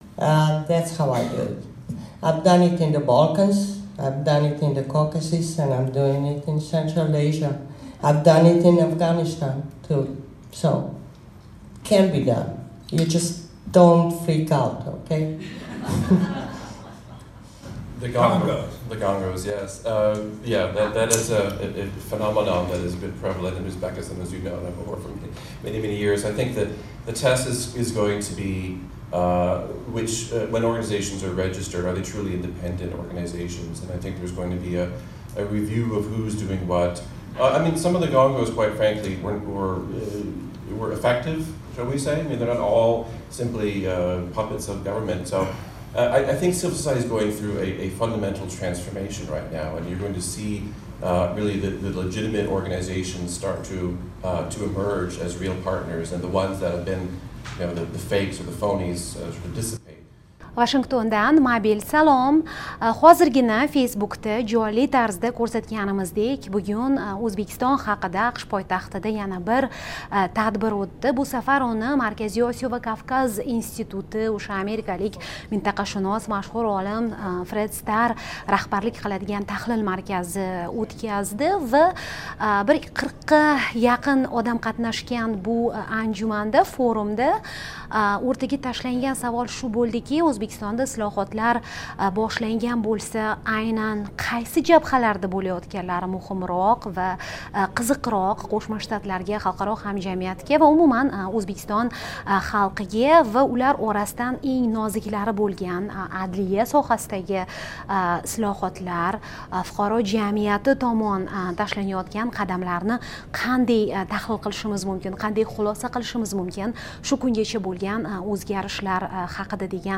O'zbekistondagi islohotlar - AQShda forum